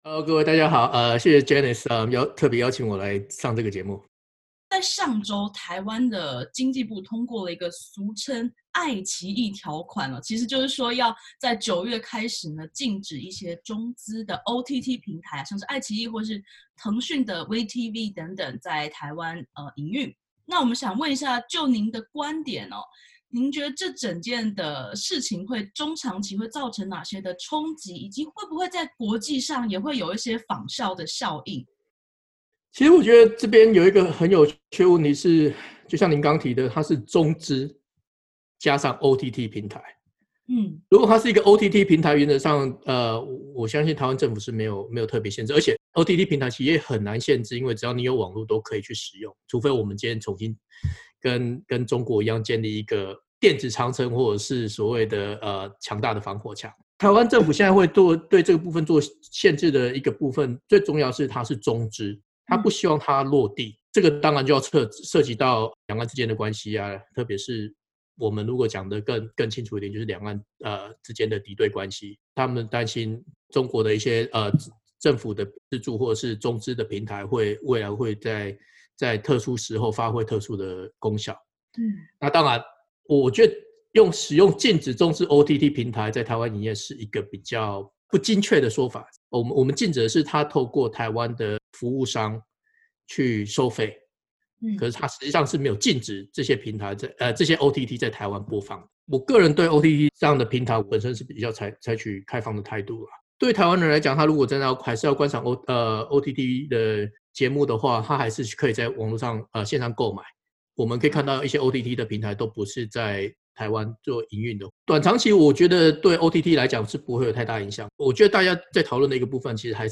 点击图片收听完整采访音频。（此为嘉宾观点） 台湾经济部日前预告，将于9月3日起，实施俗称“爱奇艺条款”，禁止台湾业者代理或经销中国的OTT平台和相关业务。